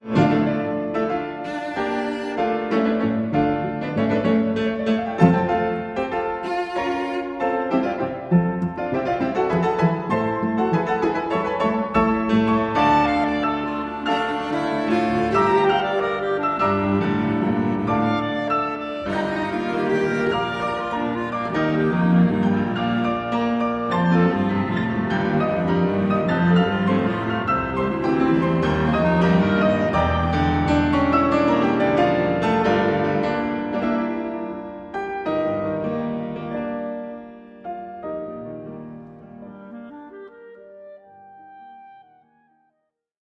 Trio for clarinet, cello and piano in D minor
Chamber Music